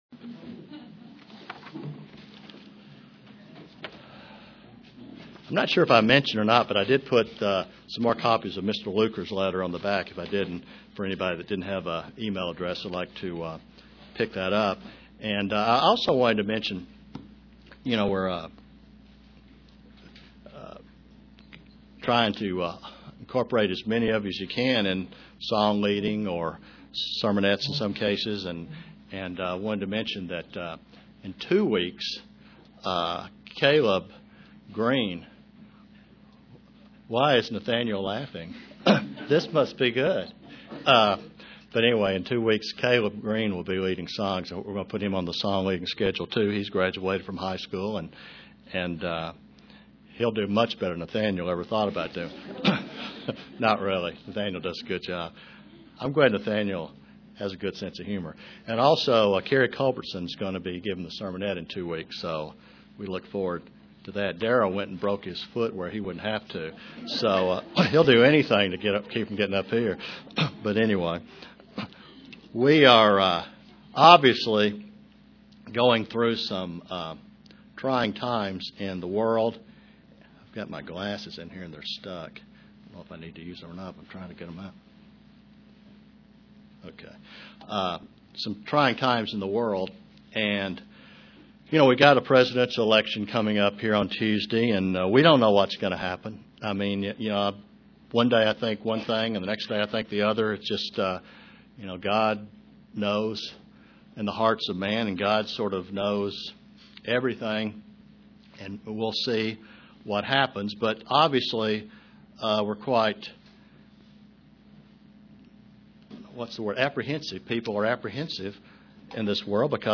Print Story of Edom/Esau and birth of the promised seed(Issac) and Ishmael UCG Sermon Studying the bible?